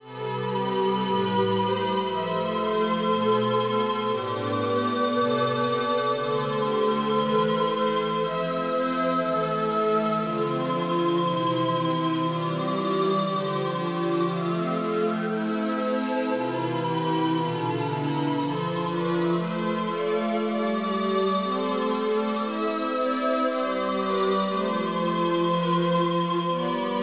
Žánr : elektronická hudba
Synth Goblin + Arches